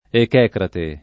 [ ek $ krate ]